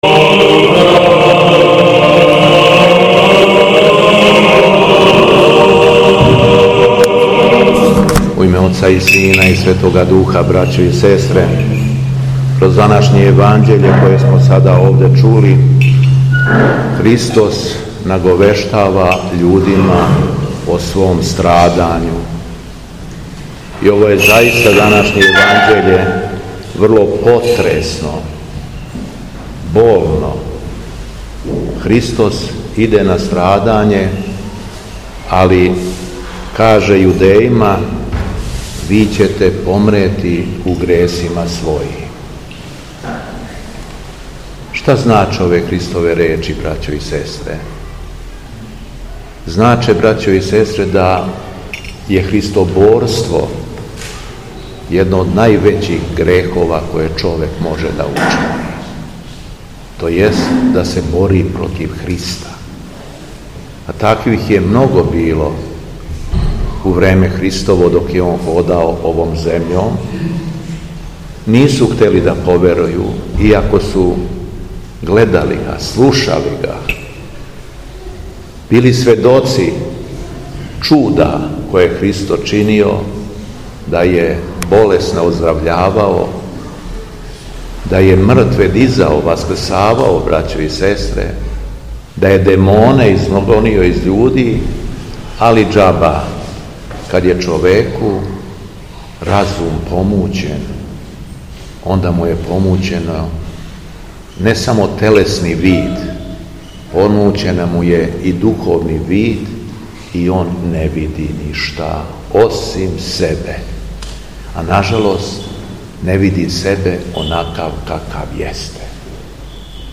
Беседа Његовог Високопреосвештенства Митрополита шумадијског г. Јована